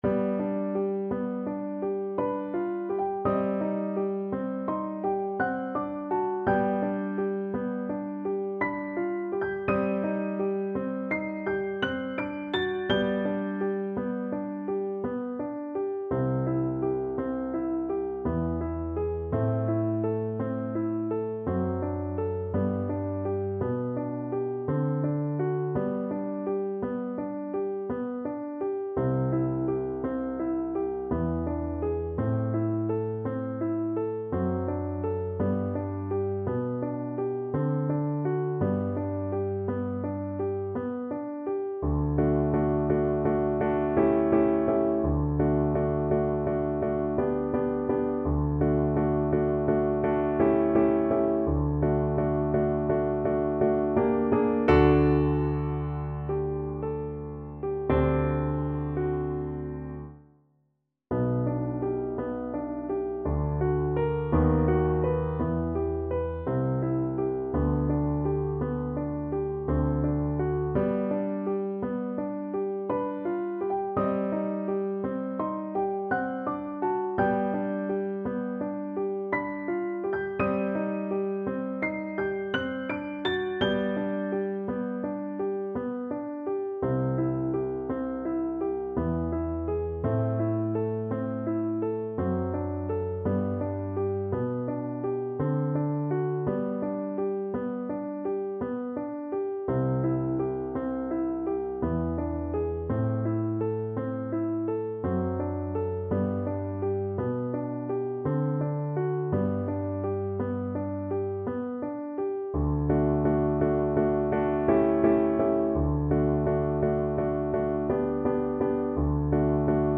Play (or use space bar on your keyboard) Pause Music Playalong - Piano Accompaniment Playalong Band Accompaniment not yet available transpose reset tempo print settings full screen
~ = 56 Ziemlich langsam
G major (Sounding Pitch) (View more G major Music for Flute )
Classical (View more Classical Flute Music)